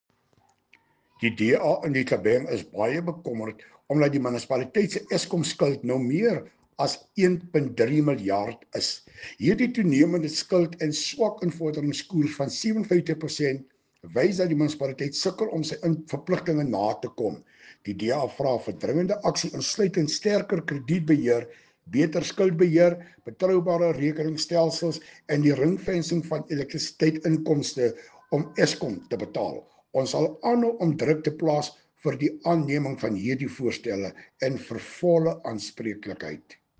Afrikaans soundbites by Cllr Hilton Maasdorp and